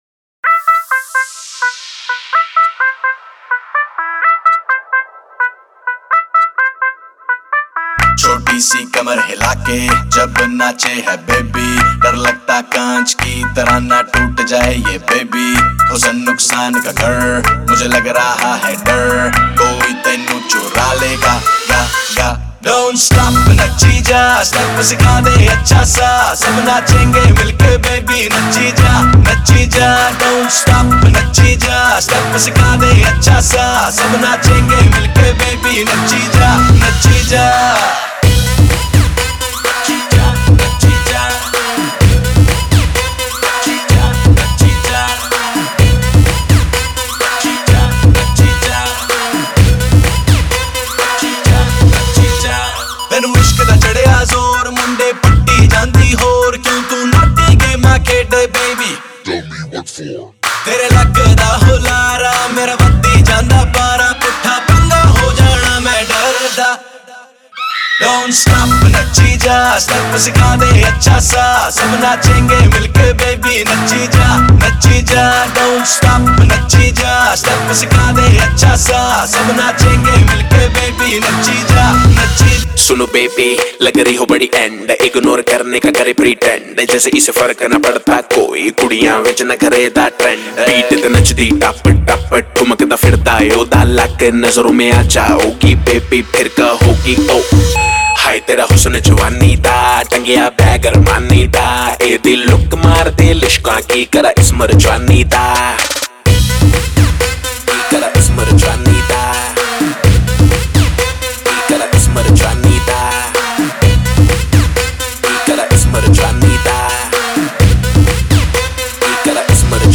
Punjabi Bhangra